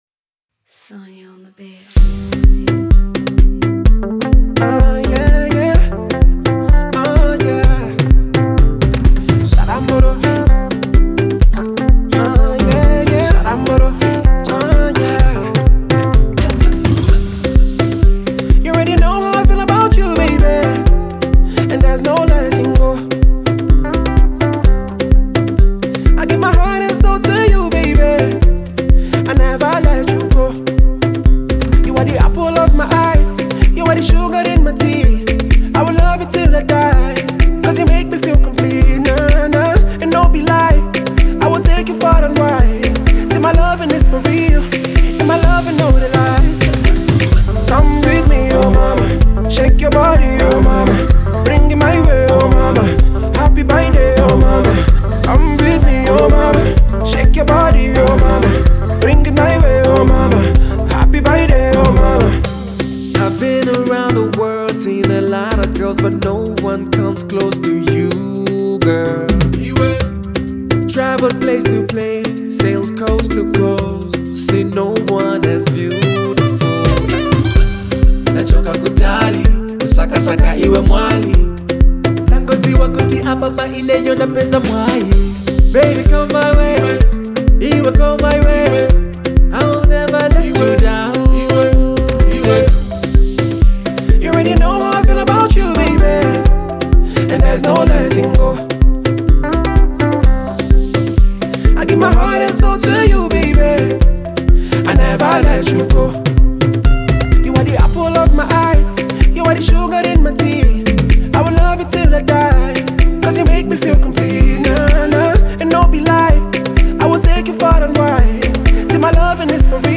Pop-African
banging summer tune